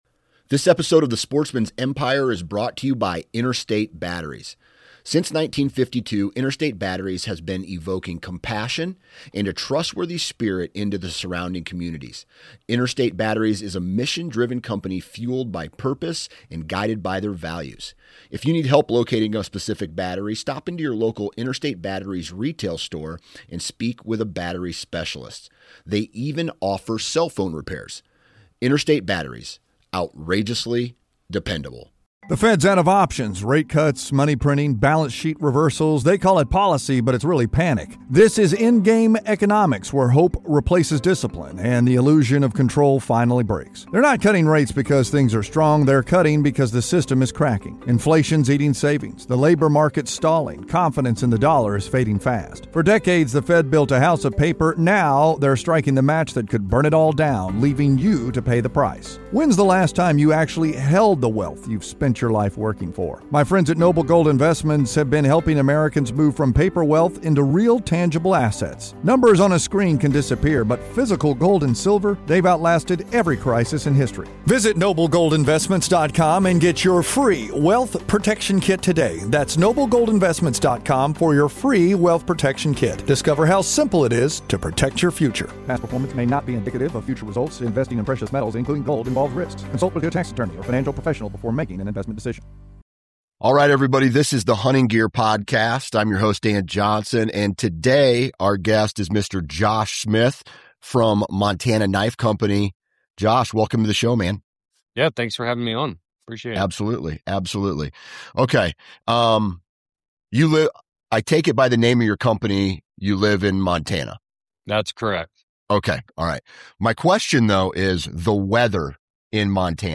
Be a guest on this podcast Language: en Genres: Hobbies , Leisure , Sports , Wilderness Contact email: Get it Feed URL: Get it iTunes ID: Get it Get all podcast data Listen Now... Hunting Packs & Junk Gear